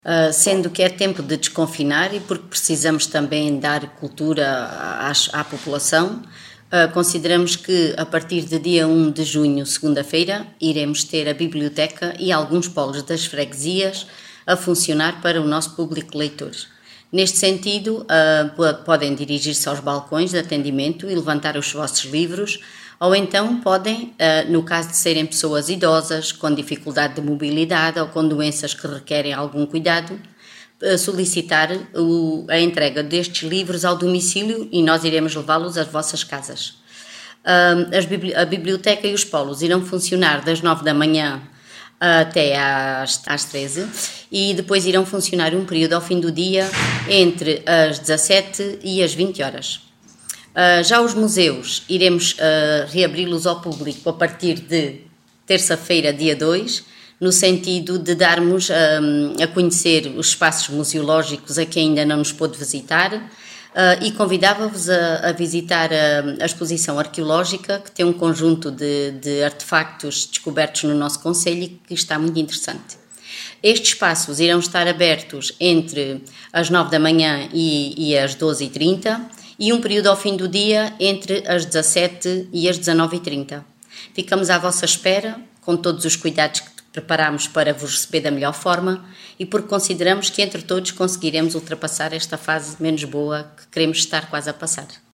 Declarações-Vereadora-Lurdes-Balola.mp3